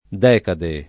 decade
decade [ d $ kade ] s. f.